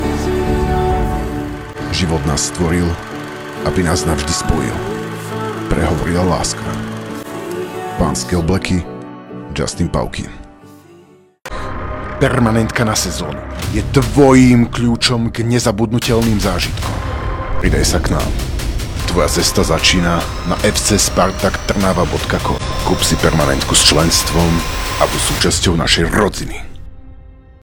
Umím: Voiceover,
Profesionálny voiceover/dabing - Muž - 60 sekúnd - SK/ENG